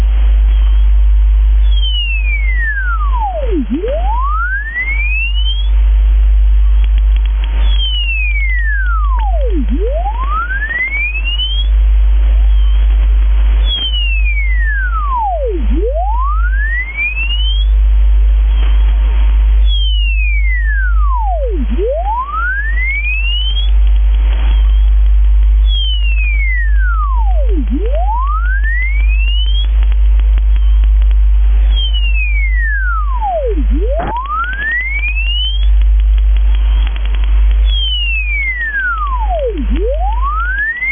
- Mysterious "V" from Israel